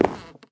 wood6.ogg